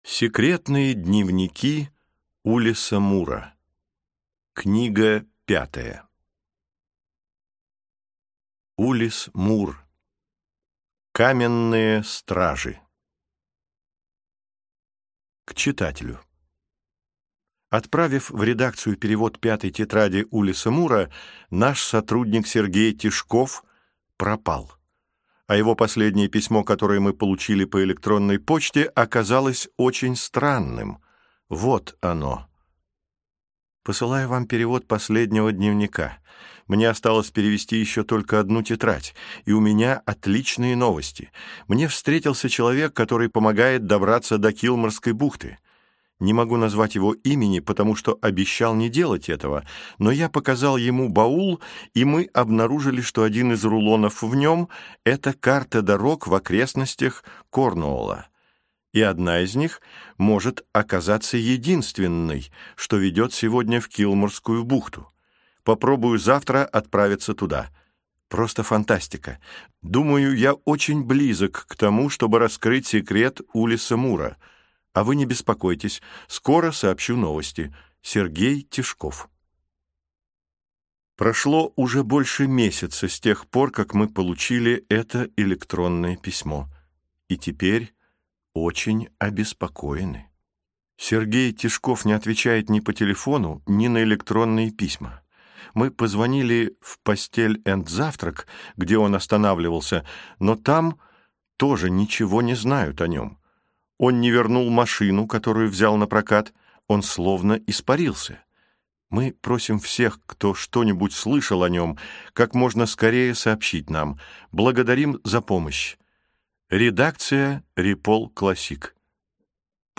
Аудиокнига Каменные стражи | Библиотека аудиокниг